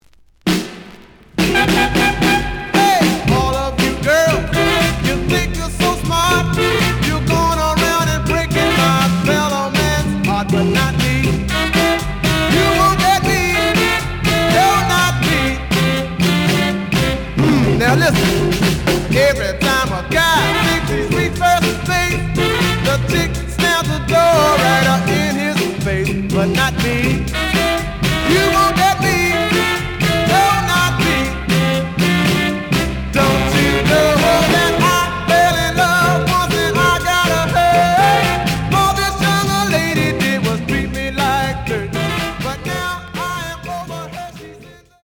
The audio sample is recorded from the actual item.
●Format: 7 inch
●Genre: Soul, 60's Soul
B side plays good.)